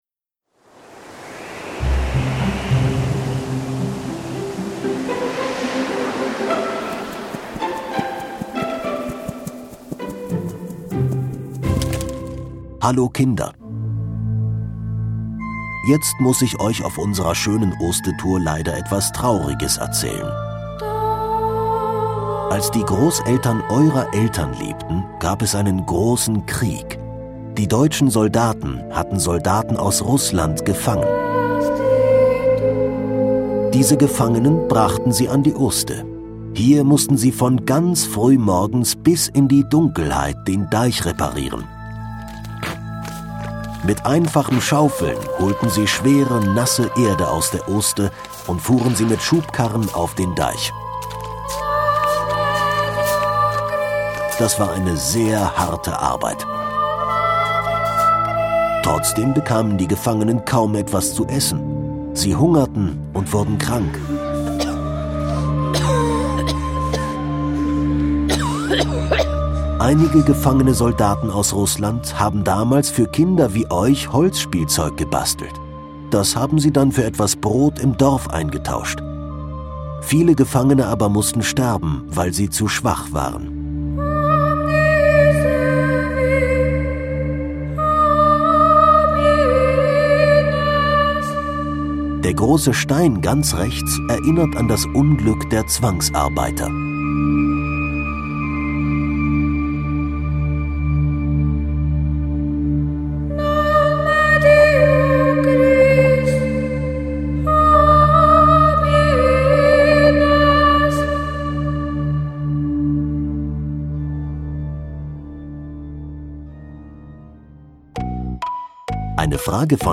Gedenkstein - Kinder-Audio-Guide Oste-Natur-Navi